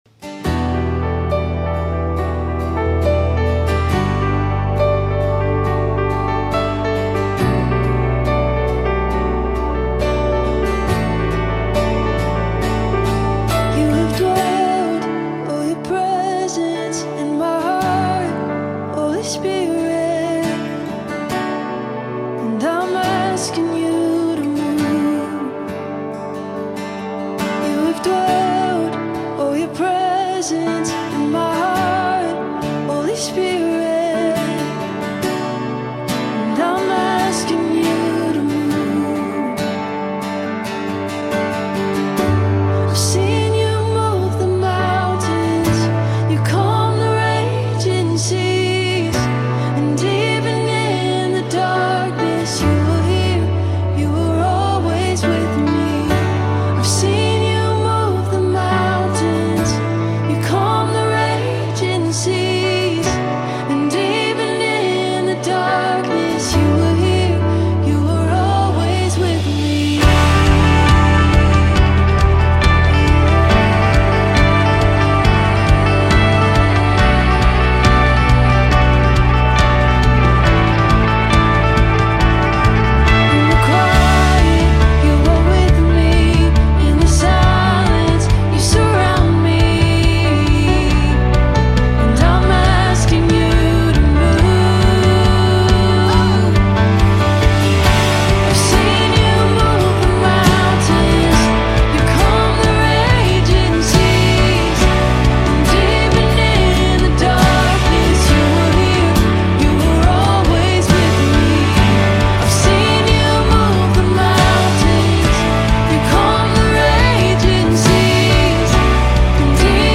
BPM: 70